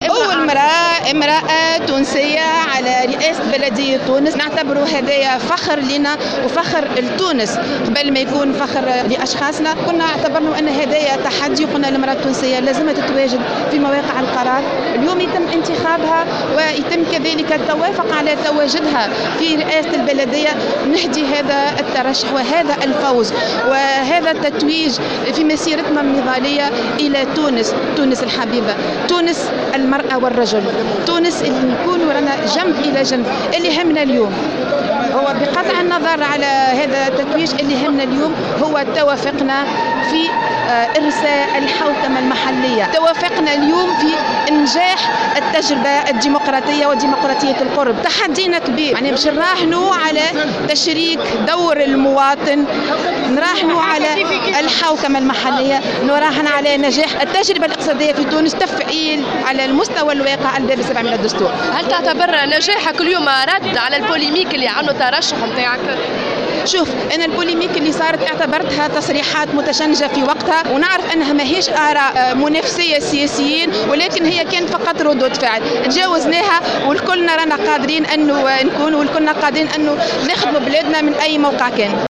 أول تصريح لسعاد عبد الرحيم بعد فوزها بمنصب "شيخ" المدينة